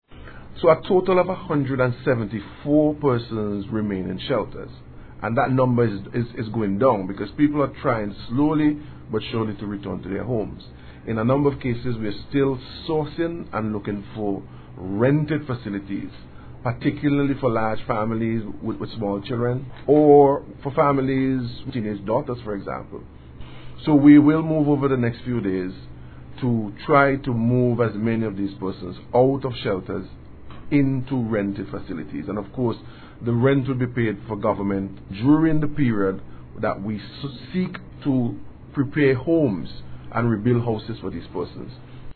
Executive Director of the National Emergency Management Organization (NEMO), Howie Prince provided an update on the recovery process at a Media Conference yesterday.